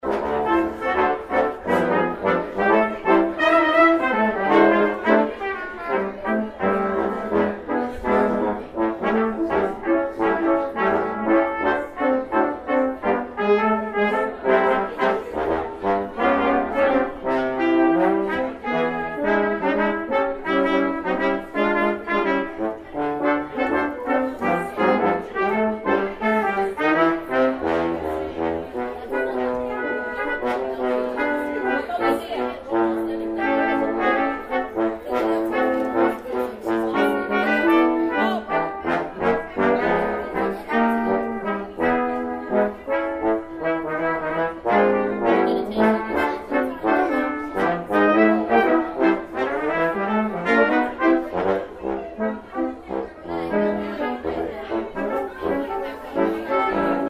music for cocktail hour (mp3).  The Quintet switches easily between styles from ceremony to reception.